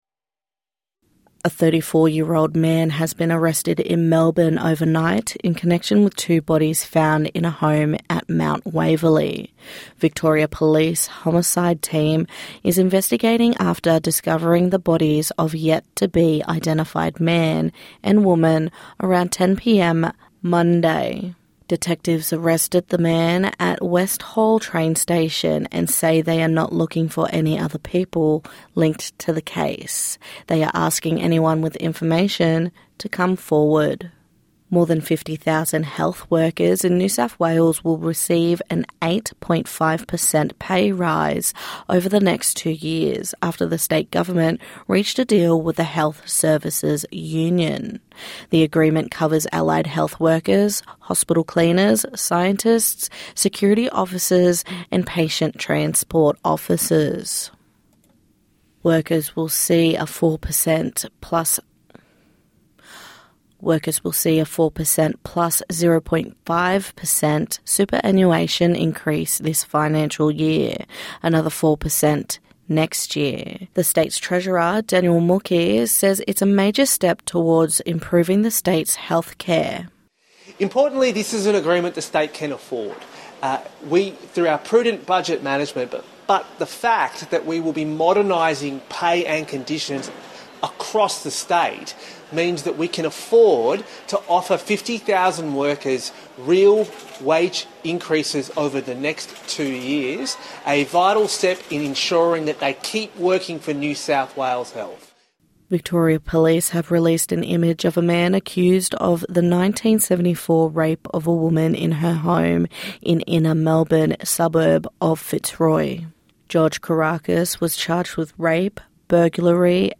NITV Radio News - 13/08/2025